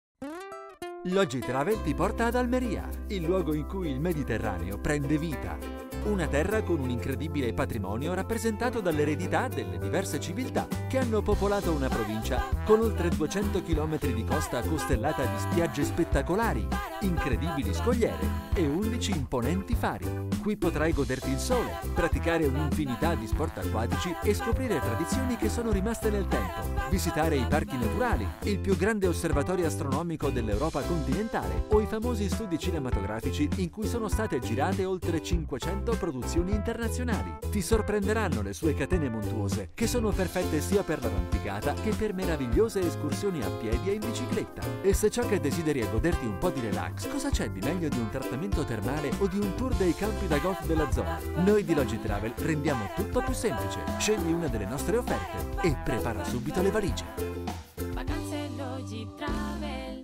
SHOWREEL